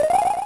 Tail Attack - Super Mario Advance 4: Super Mario Bros. 3